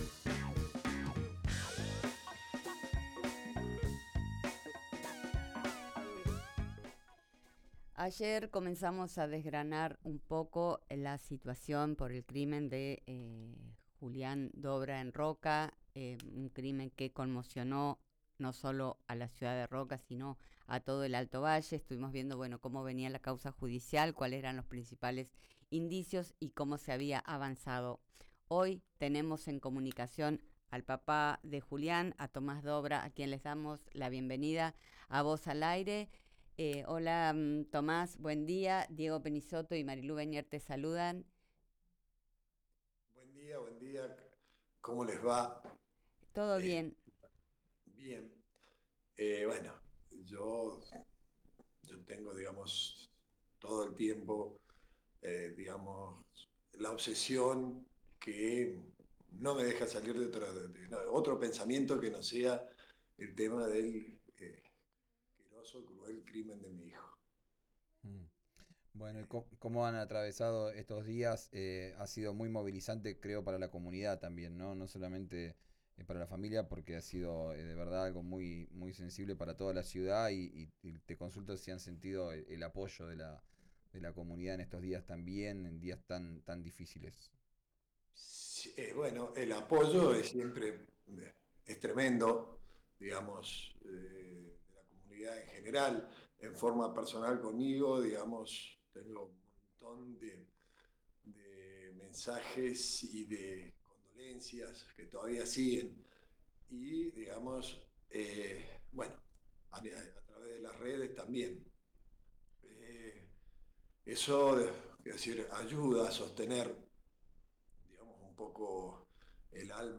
Esta vez, lo dijo en diálogo con RÍO NEGRO Radio. Volvió a apuntar contra la Fiscalía de haber actuado tarde y mal durante la desaparición del joven.